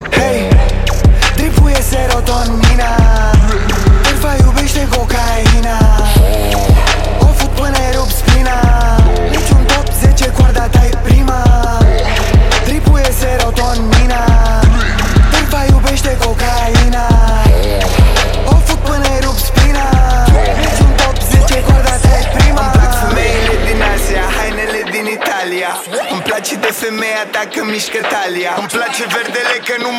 A bold rap anthem of style and power.